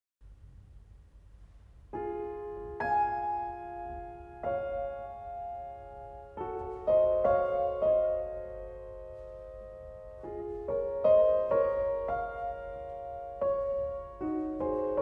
Classical, Piano